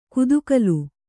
♪ kudukalu